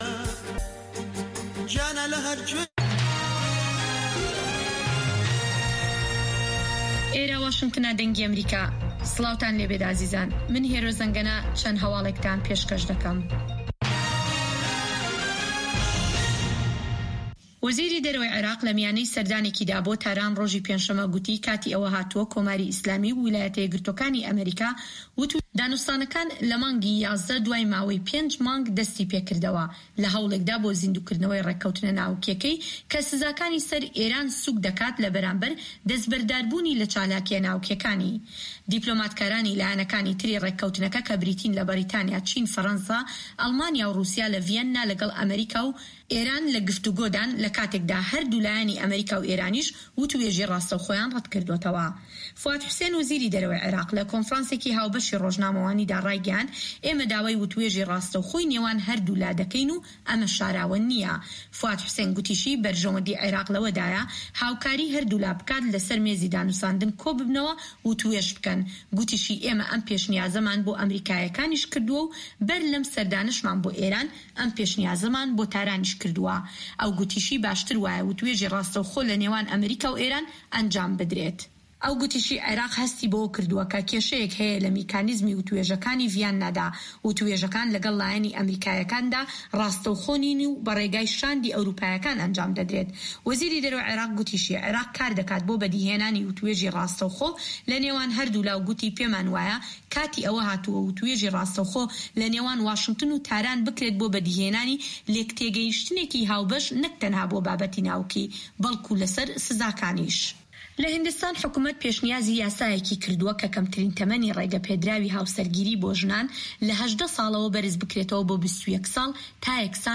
Nûçeyên 1’ê şevê
Nûçeyên Cîhanê ji Dengê Amerîka